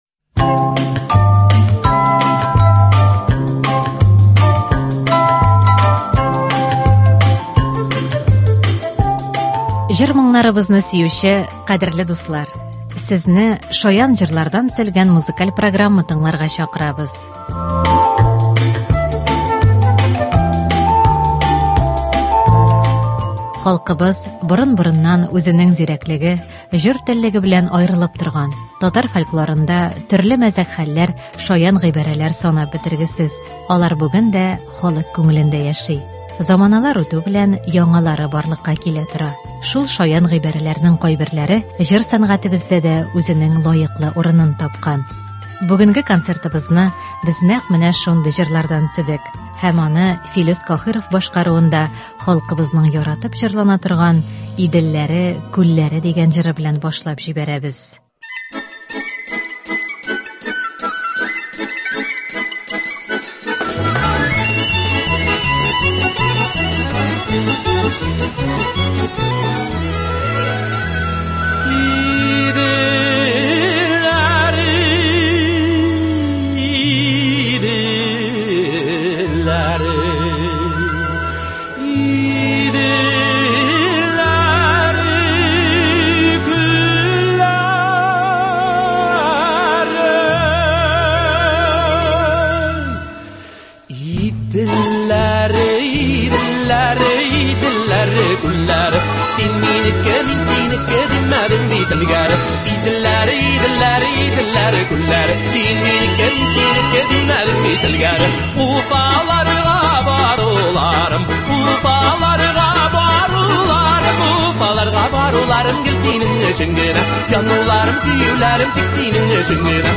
Шаян җырлар.
Концерт (01.04.21)